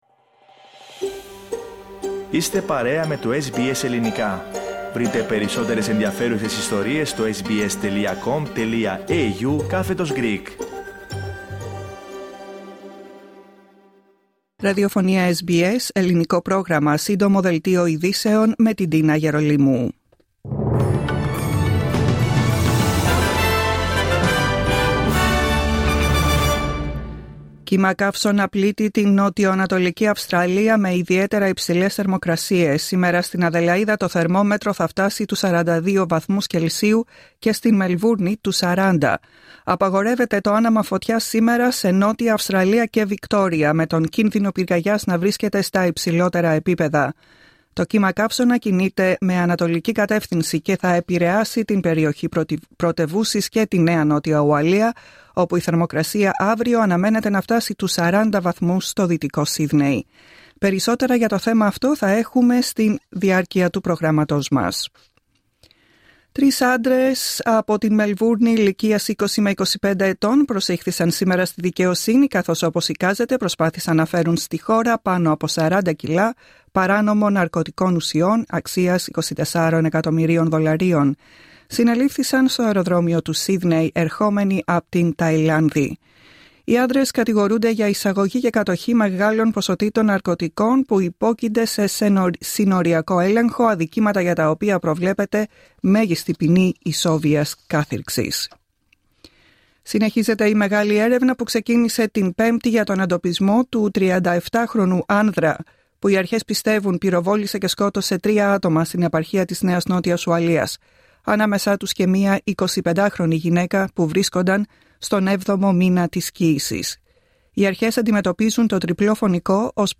Δελτίο ειδήσεων, Σάββατο 24 Ιανουαρίου 2026